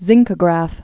(zĭngkə-grăf)